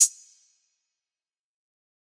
TS - HAT (3).wav